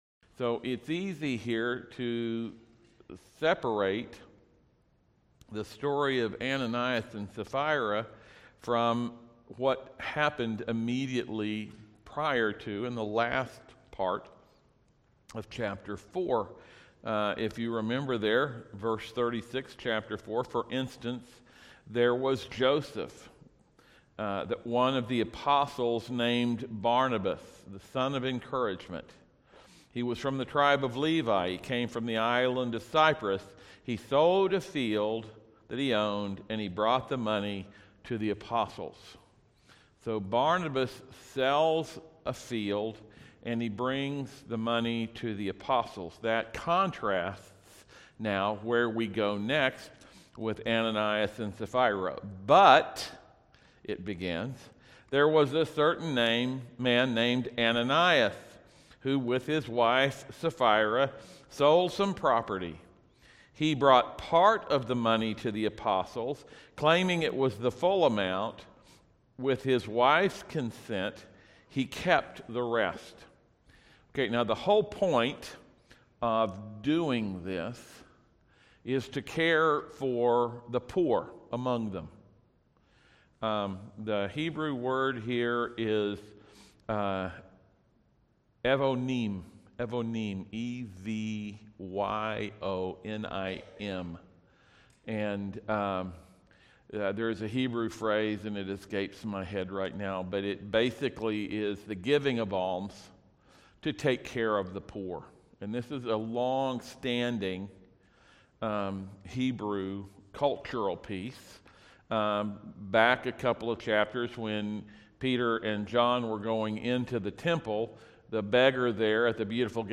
November-27-PM-Sermon-Audio.mp3